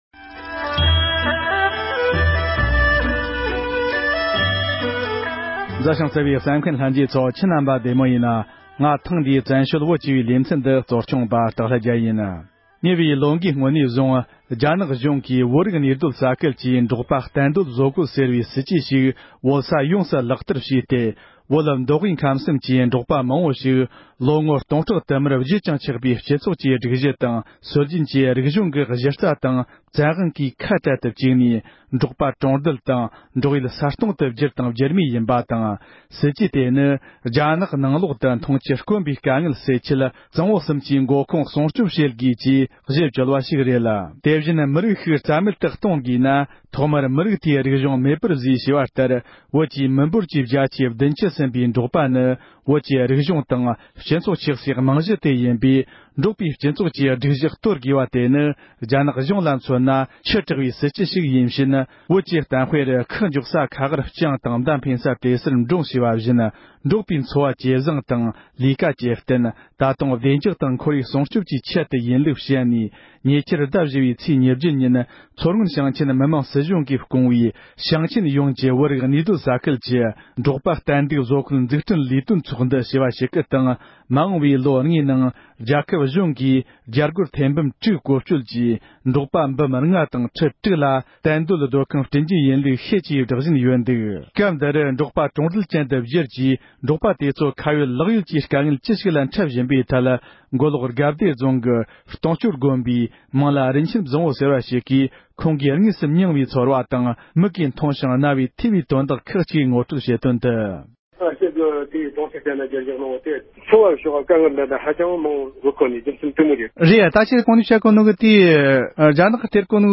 རྒྱ་གཞུང་གི་འབྲོག་པ་གྲོང་རྡལ་དུ་བསྒྱུར་ཐབས་ཀྱི་སྲིད་བྱུས་དེའི་སྐོར་འབྲེལ་ཡོད་མི་སྣ་ཁག་གིས་འགྲེལ་བརྗོད་གནང་བ།